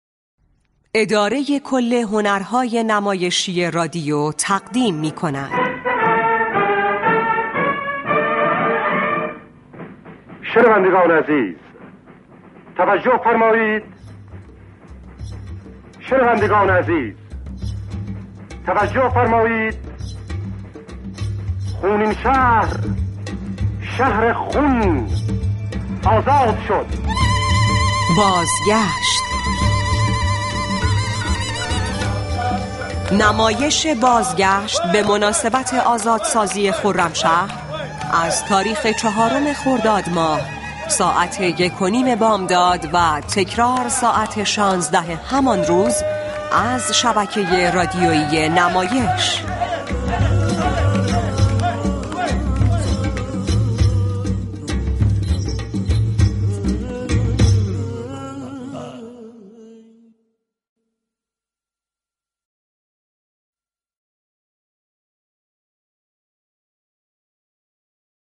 اداره كل هنرهای نمایشی رادیو ، به مناسبت سالروز آزادسازی خرمشهر، مجموعه رادیویی «بازگشت » را تولید كرد.